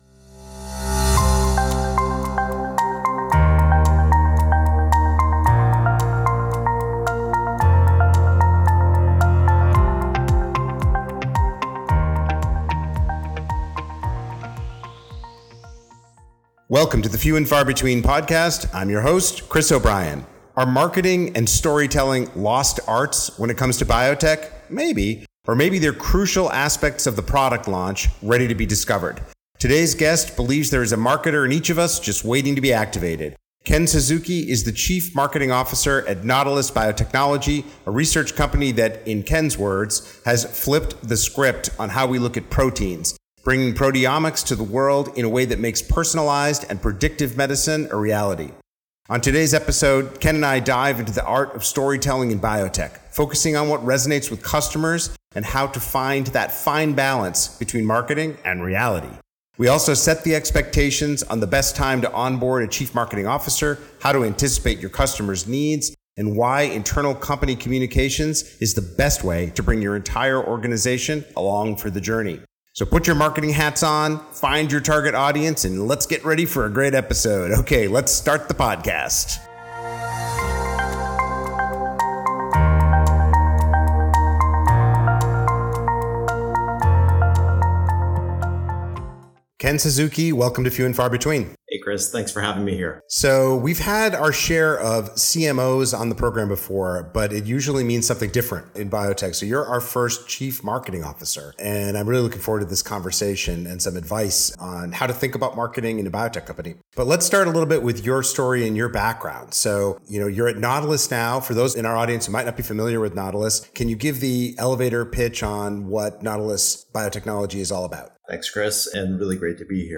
Listen in as we access the marketer inside each of us, discuss how knowing people might be more important than business and finance, and follow the steps to maintain the fine balance between marketing and reality.